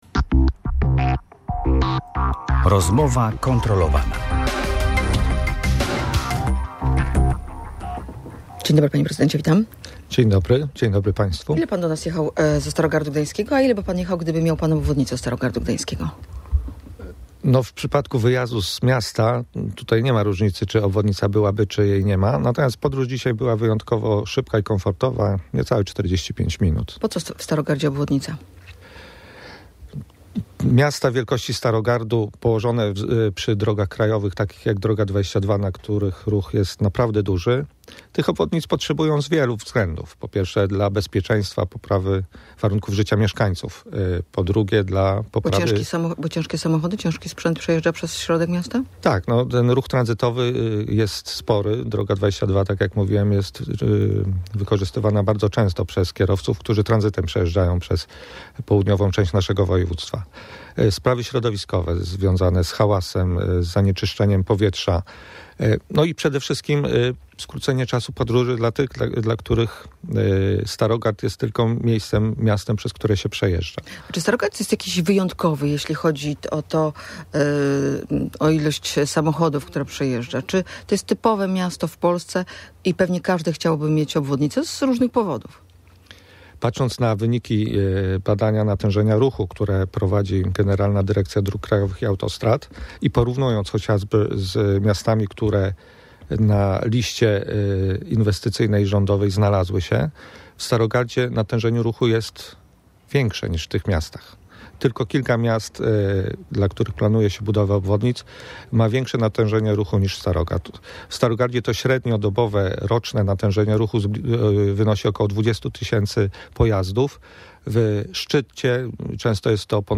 - Politycy bardziej powinni wziąć się za czyny, a mniej mówić w tej sprawie - mówi wiceprezydent Starogardu Gdańskiego Przemysław